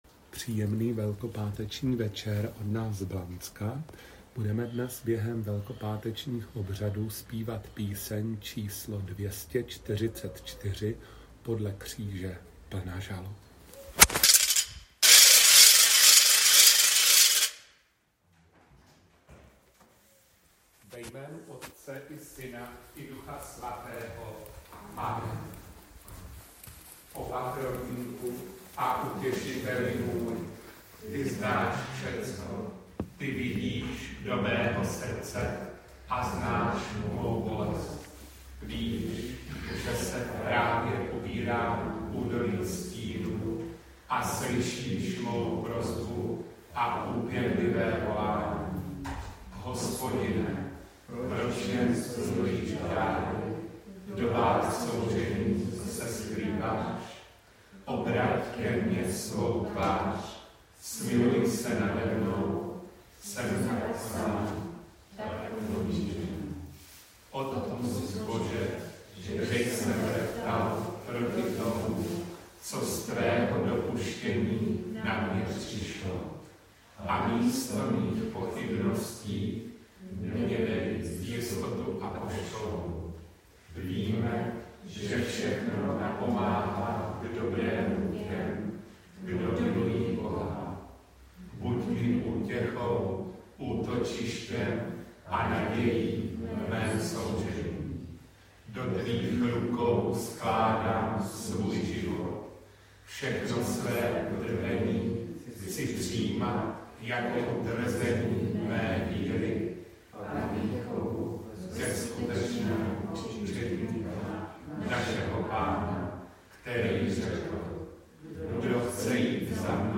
Přehled záznamů bohoslužeb Velkého Pátku